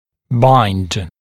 [baɪnd][байнд]вступать в сцепление (обыч. о взаимодействии дуги и паза брекета)